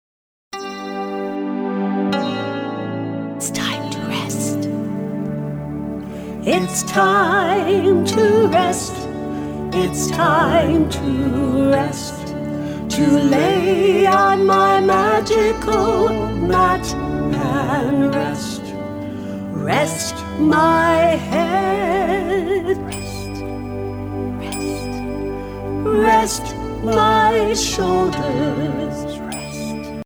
calming and alerting songs
CALMING SONGS ALERTING SONGS